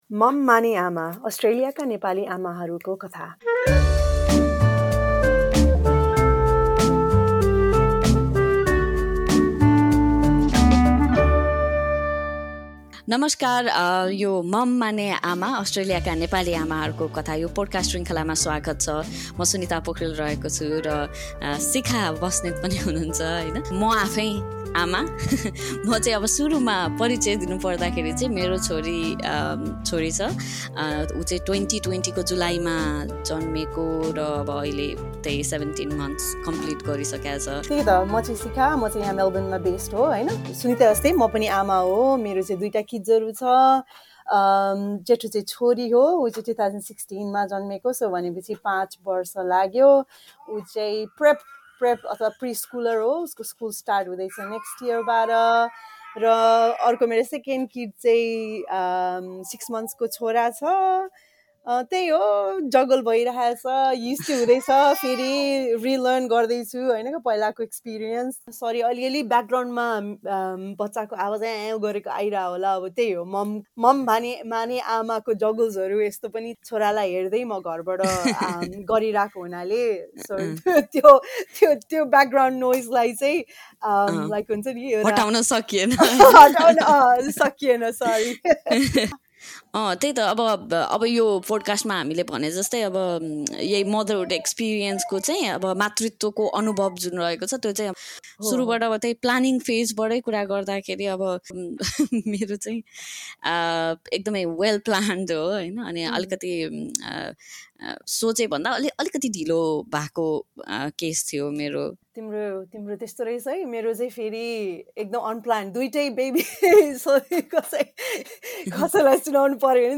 chat to a few Nepali mums in Australia who share their experiences about falling pregnant, unplanned pregnancy and what to do when things don’t go to plan.